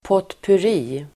Ladda ner uttalet
Uttal: [påtpur'i:]